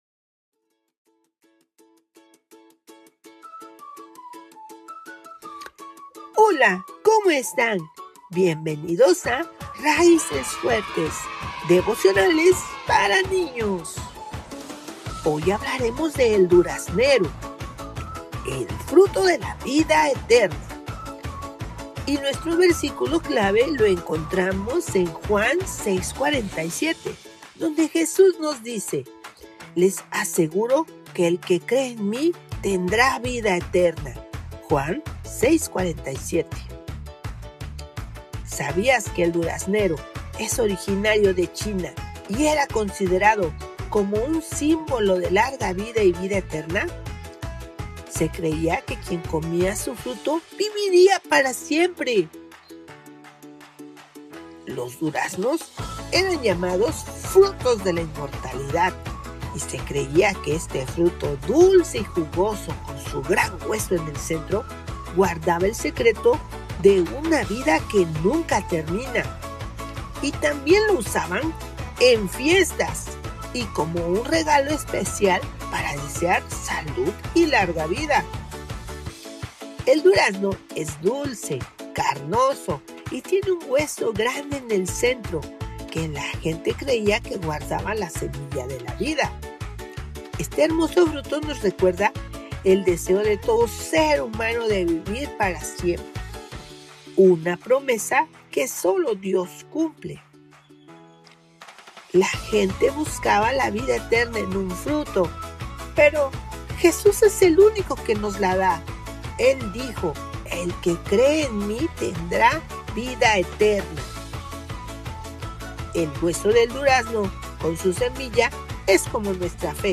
Raíces Fuertes – Devocionales para Niños